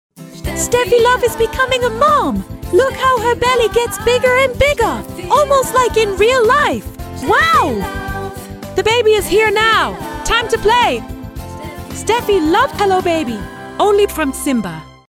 Qualität: Unsere KI-Stimmen liefern Ergebnisse, die kaum von menschlichen Sprechern zu unterscheiden sind.
KI Frau Englisch:
KI-Frau-EN.mp3